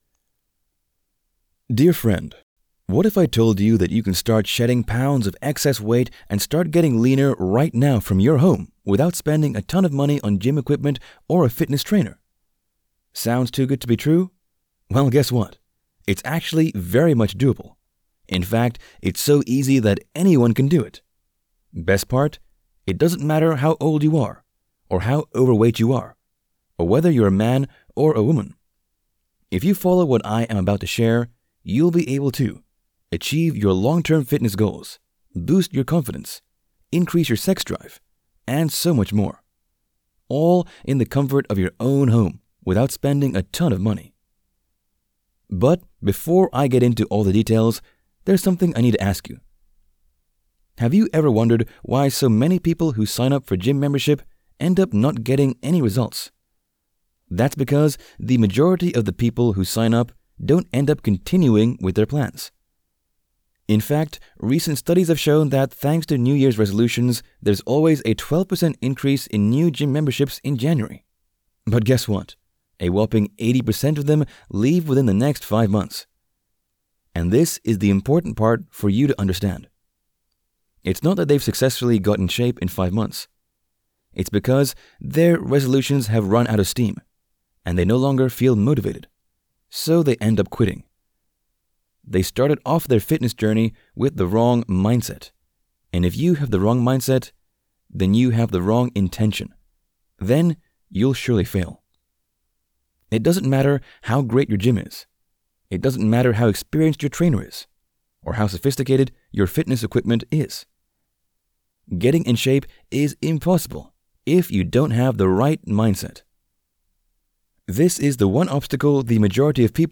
Voiceover.mp3